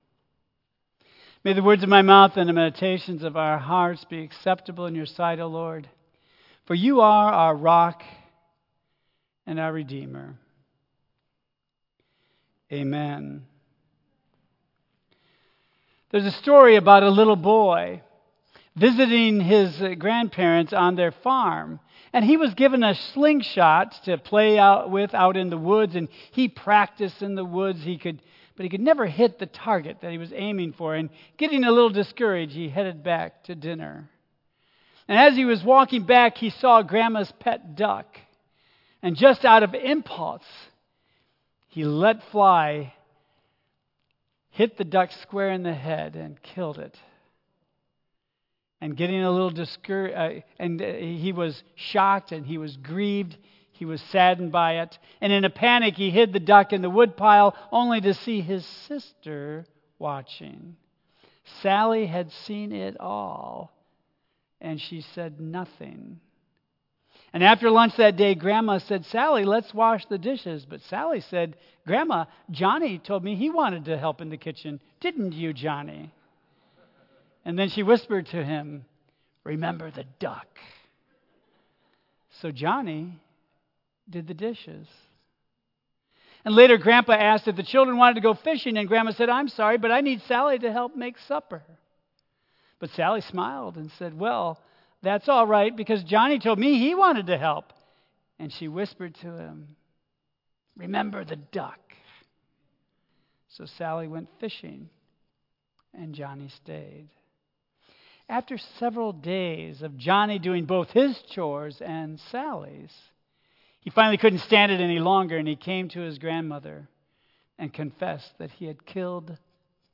Praying with Jesus Message Series Good Friday Worship Jesus offered seven last words from the cross.
Tagged with Lent , Michigan , Sermon , Waterford Central United Methodist Church , Worship Audio (MP3) 6 MB Previous Jesus's Final Desires Next Unexpected Blessings